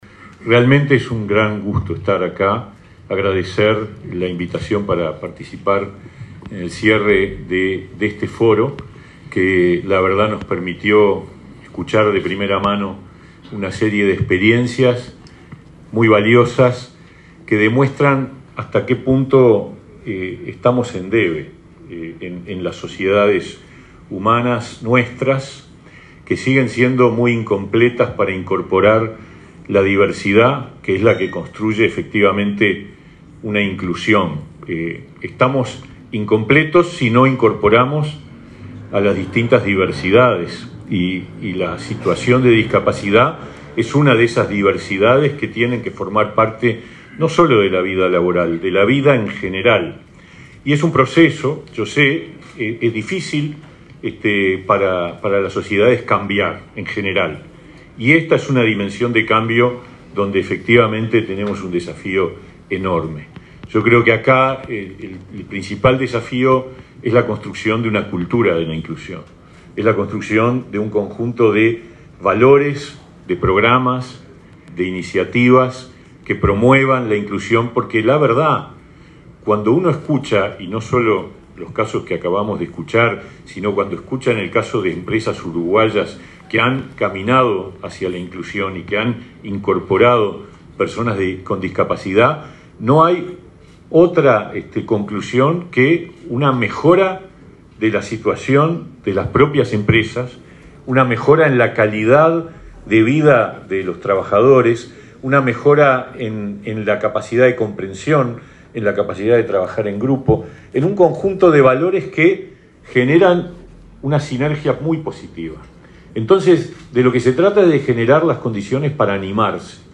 Palabras del ministro de Trabajo, Pablo Mieres
El ministro de Trabajo, Pablo Mieres, participó este miércoles 26 en el acto de clausura del VIII Foro Iberoamérica Incluye, realizado en Montevideo.